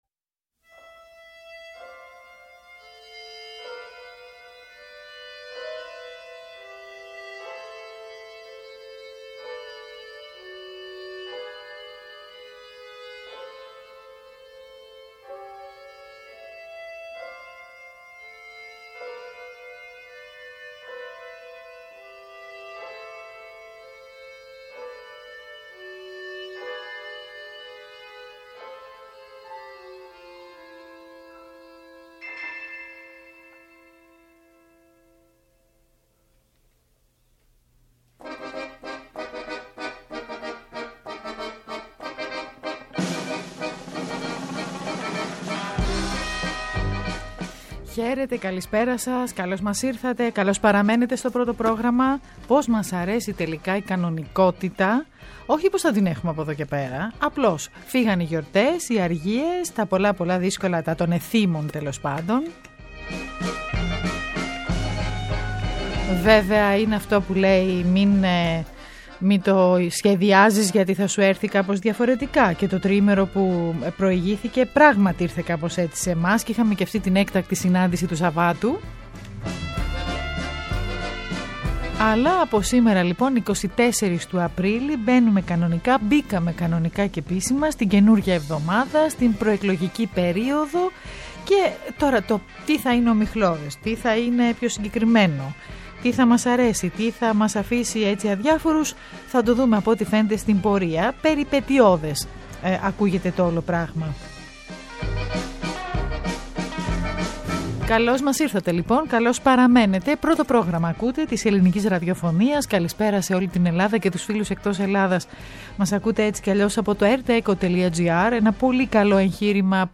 Καλεσμένος ο δημοσιογράφος